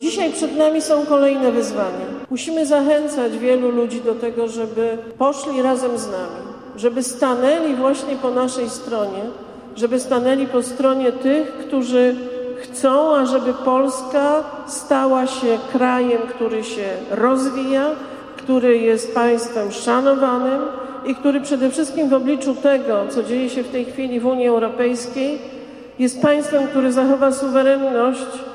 W Sieradzu z sympatykami Prawa i Sprawiedliwości spotkała się dziś (03.02) wiceprezes PiS, eurodeputowana, Beata Szydło